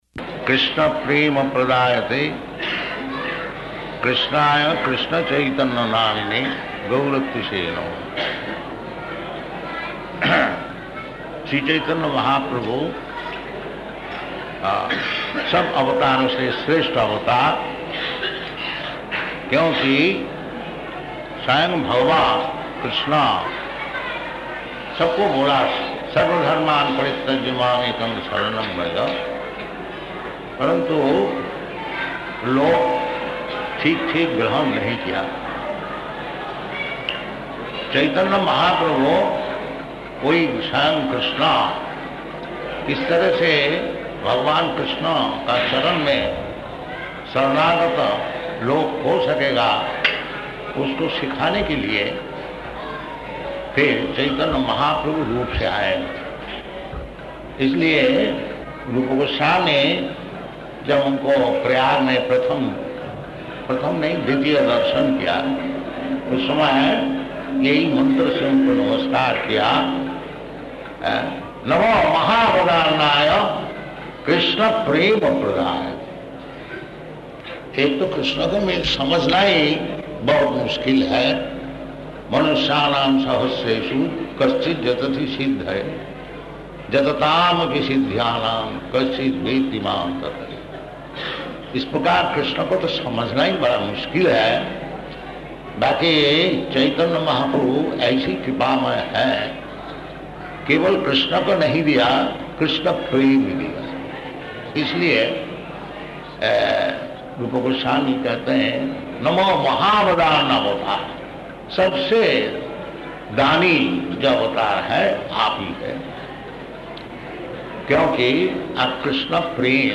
Lecture in Hindi
Lecture in Hindi --:-- --:-- Type: Lectures and Addresses Dated: April 8th 1976 Location: Vṛndāvana Audio file: 760408LE.VRN.mp3 Prabhupāda: [Hindi] Śrīmad-Bhāgavatam 7.9.53 Speech in Hindi [partially recorded]